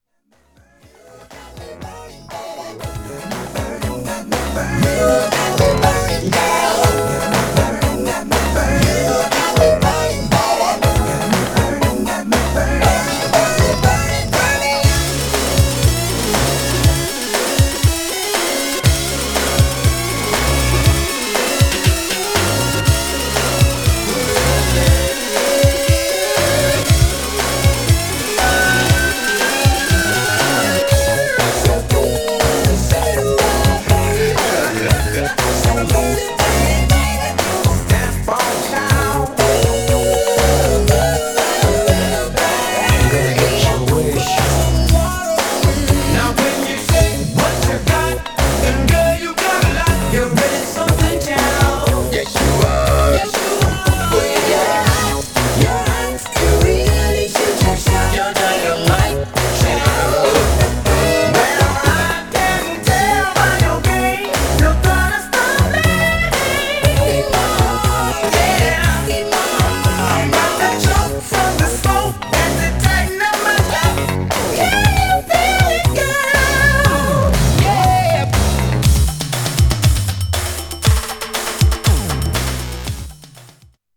Styl: Disco